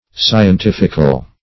Scientifical \Sci`en*tif"ic*al\, a.